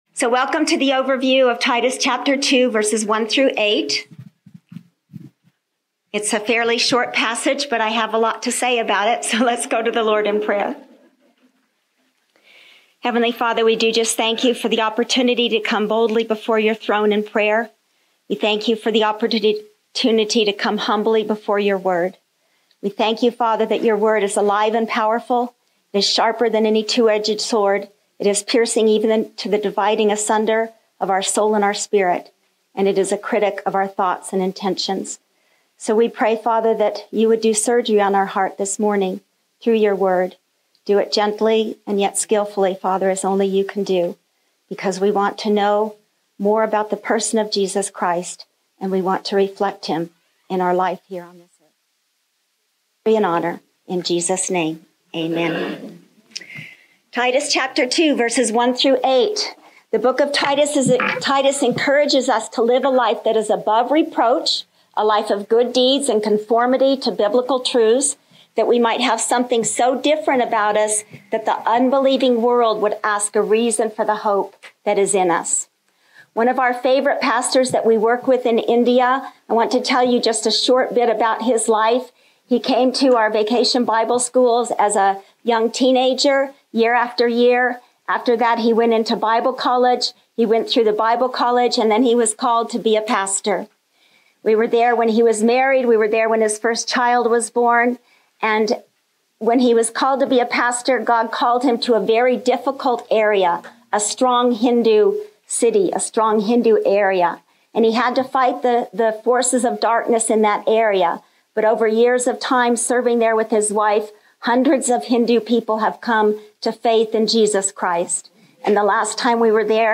All Lessons (latest first)